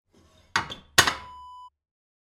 Wood burning kitchen stove door close sound effect .wav #2
Description: The sound of closing the door of a wood burning kitchen stove
Properties: 48.000 kHz 24-bit Stereo
A beep sound is embedded in the audio preview file but it is not present in the high resolution downloadable wav file.
Keywords: wood, burn, burning, kitchen, stove, oven, fire, metal, hatch, close, closing
wood-burning-kitchen-stove-door-close-preview-2.mp3